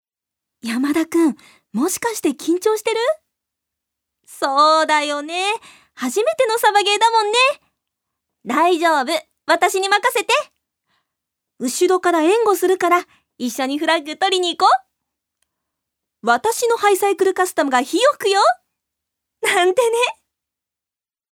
女性タレント
セリフ１